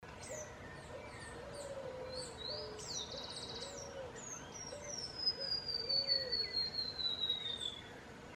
Arañero Silbón (Myiothlypis leucoblephara)
Nombre en inglés: White-rimmed Warbler
Fase de la vida: Adulto
Localidad o área protegida: Parque Nacional Ciervo de los Pantanos
Condición: Silvestre
Certeza: Vocalización Grabada